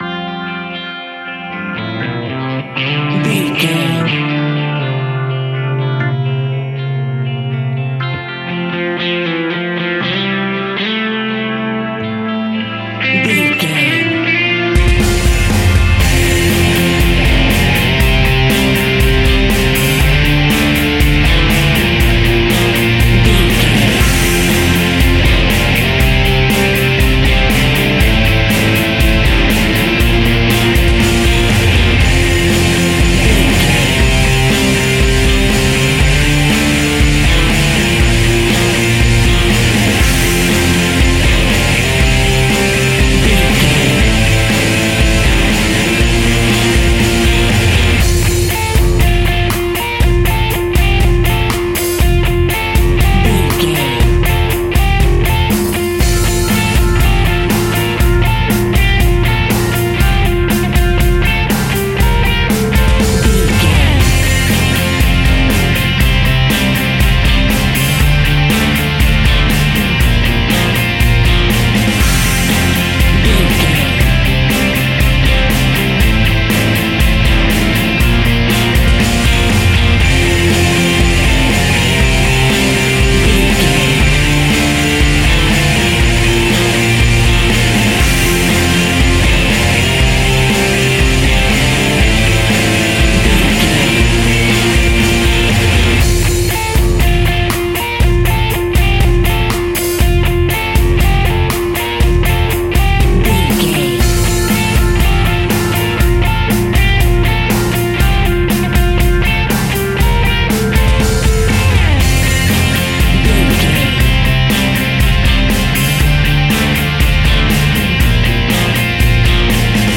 Epic / Action
Fast paced
Ionian/Major
heavy metal
heavy rock
blues rock
distortion
hard rock
Instrumental rock
drums
bass guitar
electric guitar
piano
hammond organ